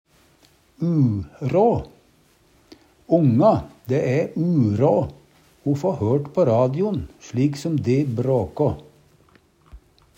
DIALEKTORD PÅ NORMERT NORSK urå uråd, vanskeleg, umogleg, let seg ikkje gjera Eksempel på bruk Onga, dæ æ urå o få hørt på radio'n slik som de bråkå.